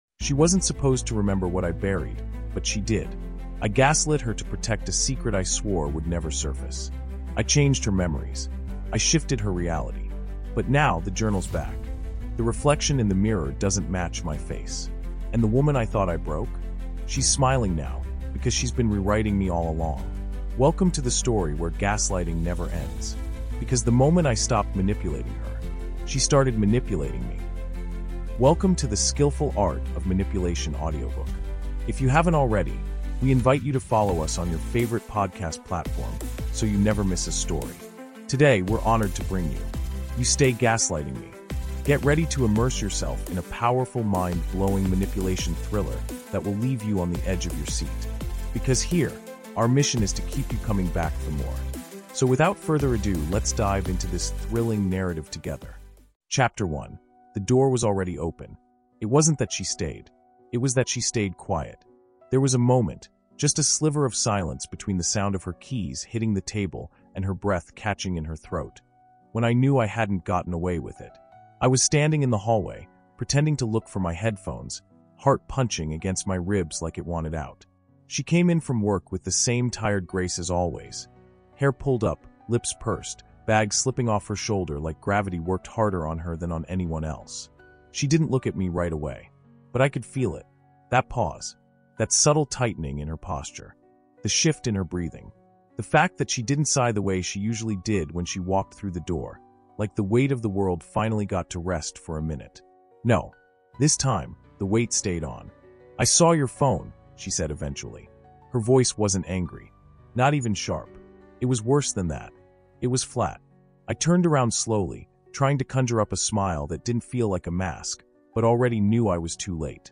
You Stay Gaslighting Me | Audiobook